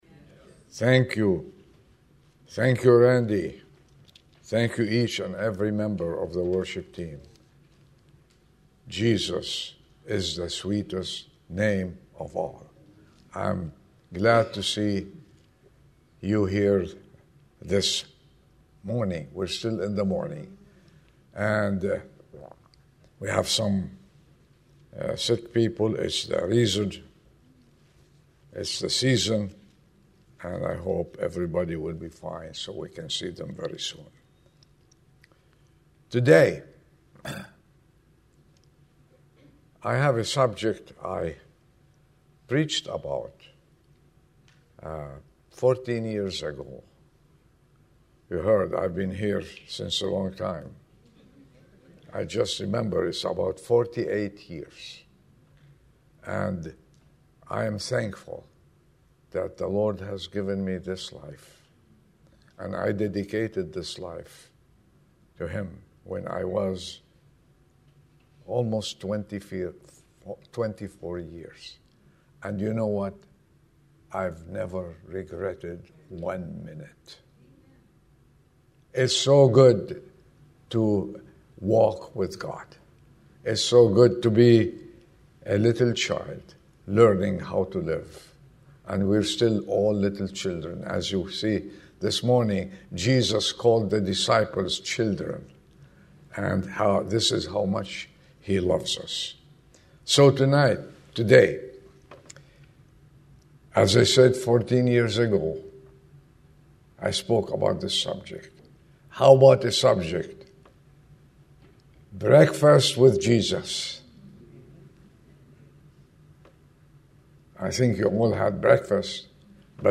Inspirational sermons from the San Ramon Valley Bible Church in San Ramon, California